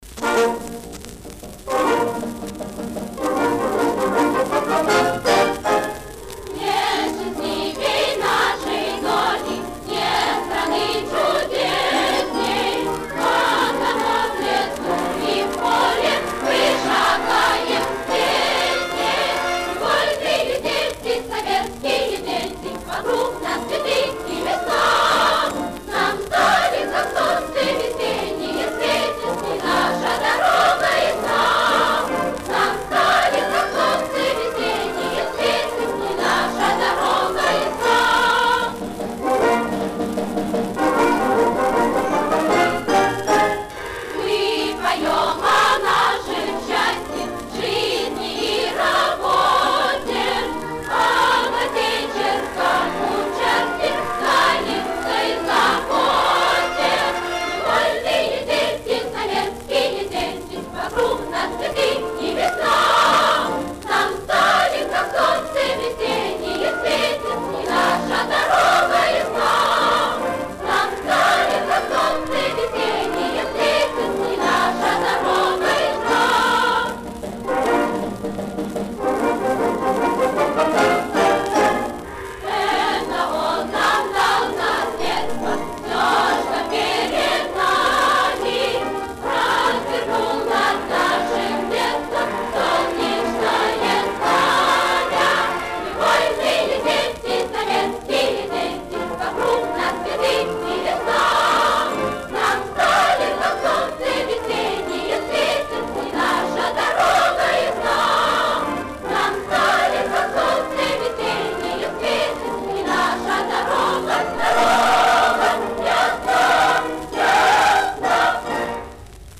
Предвоенная пионерская песня.